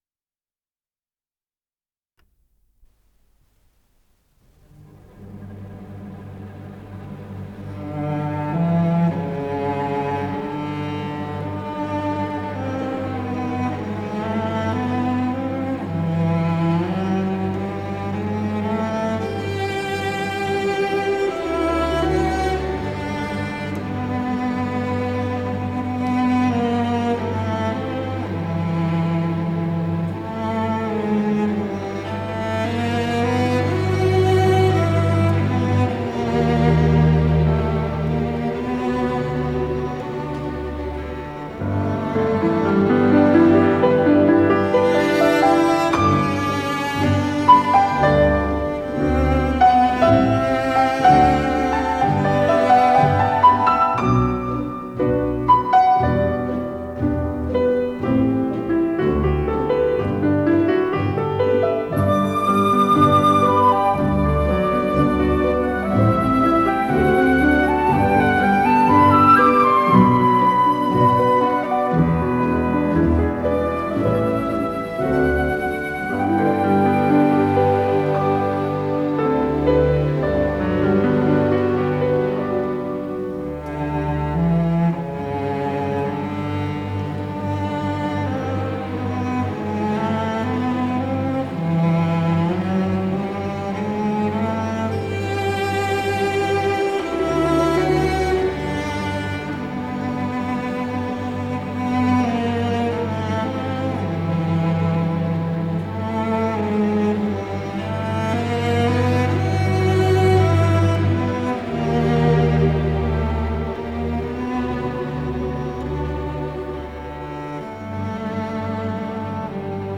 с профессиональной магнитной ленты
ПодзаголовокЗаставка, соль мажор